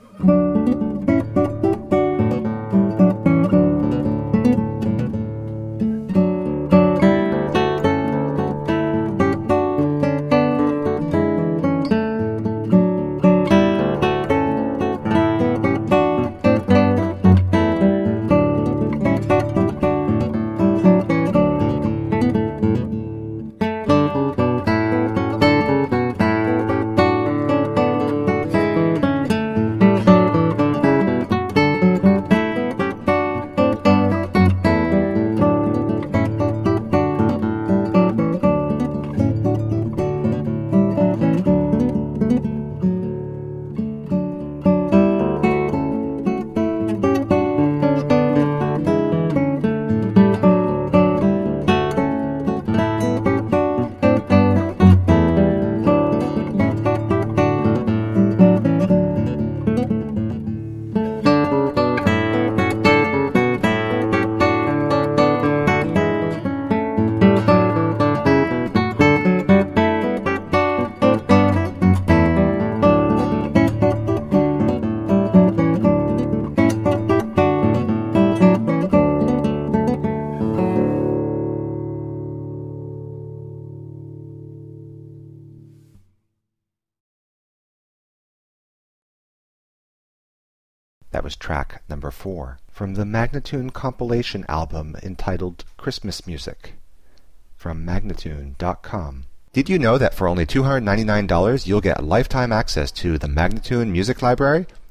carols
simple acoustic guitar